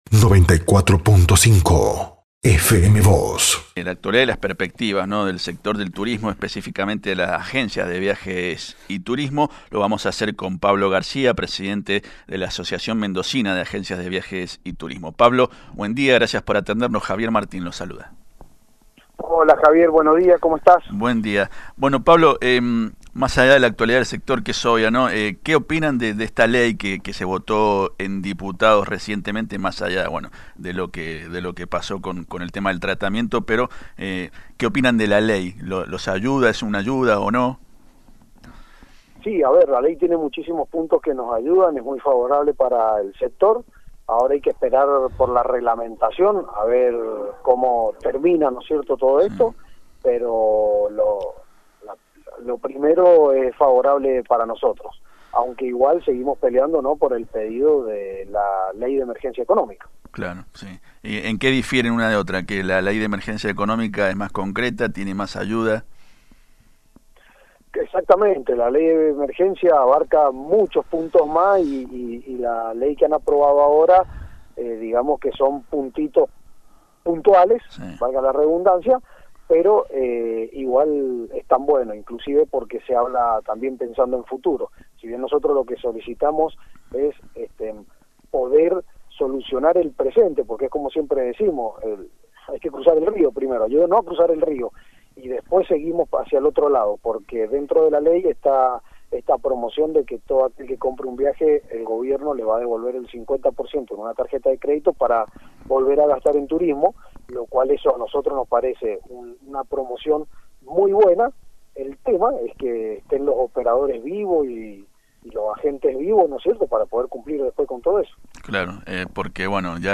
Sobre este y otros temas dialogamos en FM Vos (94.5)